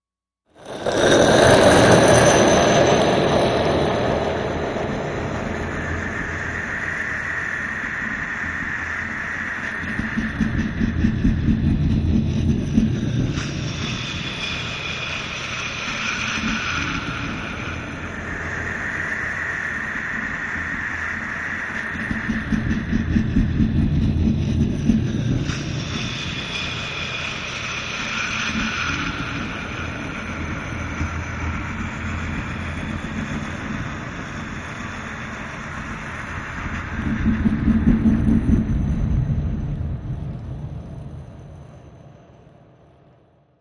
Brick Wind Cave Rock Drag Echoes Subway Wind Sweeps